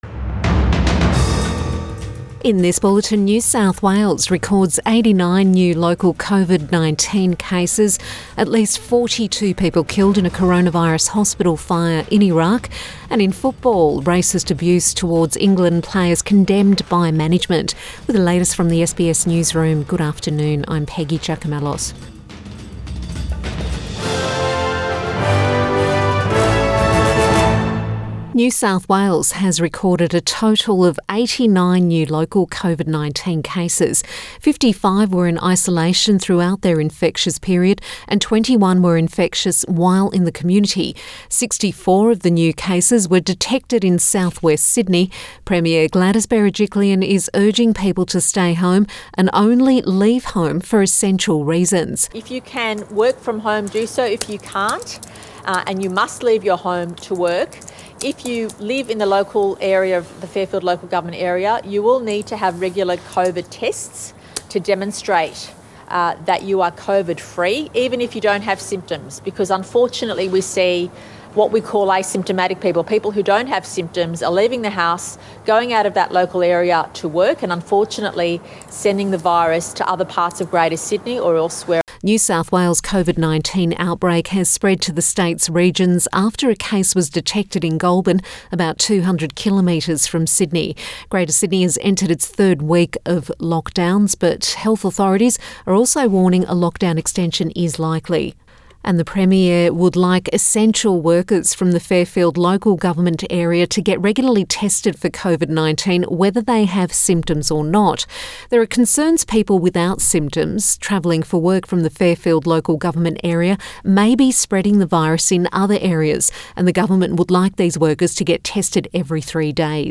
Midday bulletin 13 July 2021